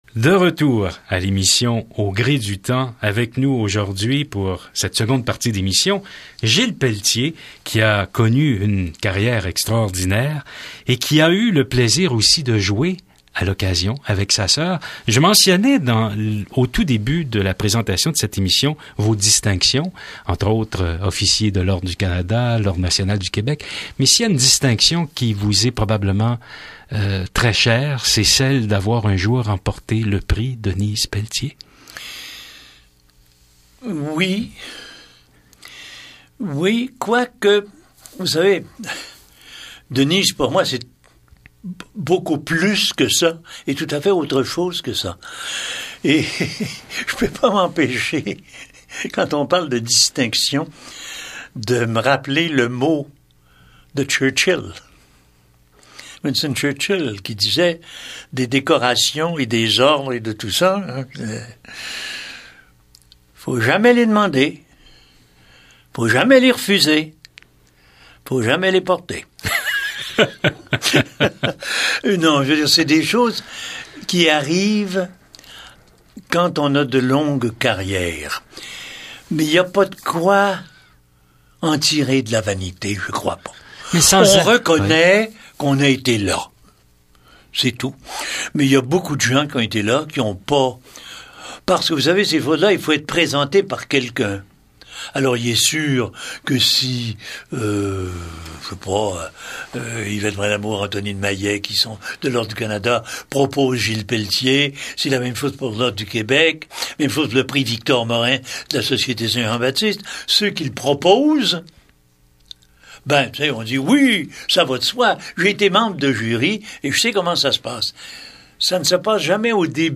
Entrevue radio avec Gilles Pelletier (22 mars 1925 – 5 septembre 2018) .